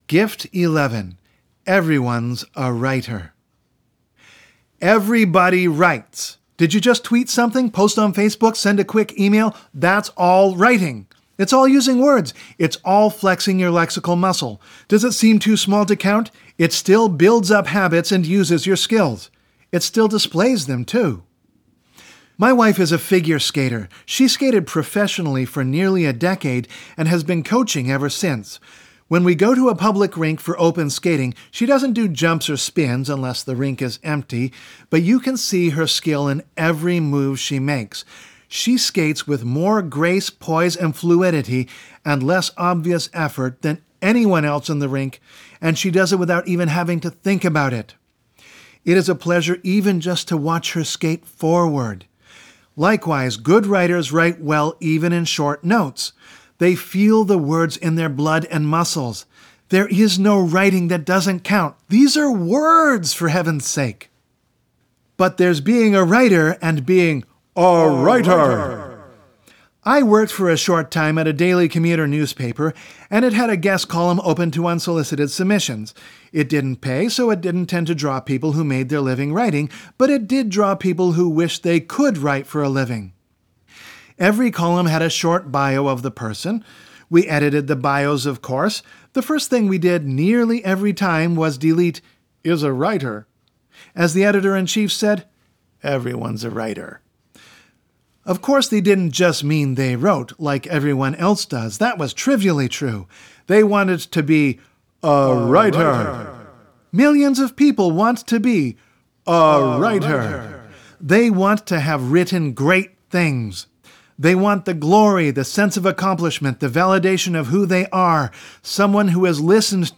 12 Gifts for Writers audiobook